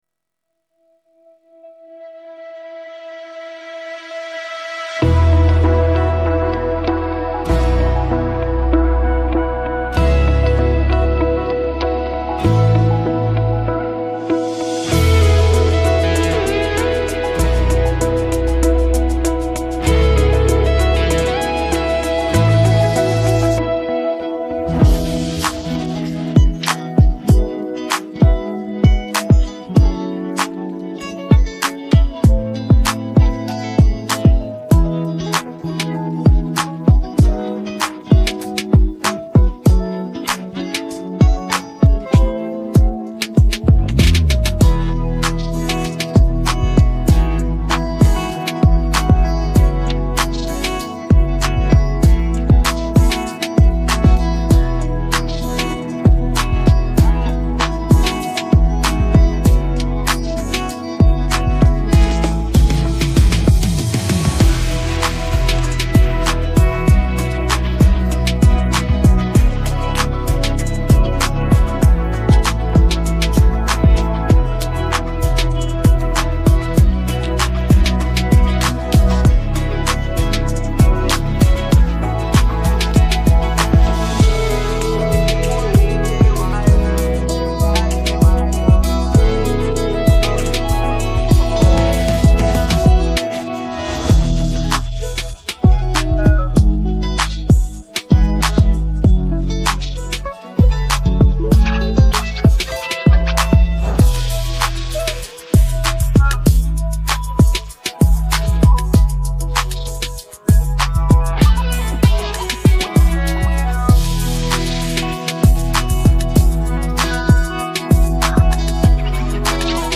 เพลงร้ย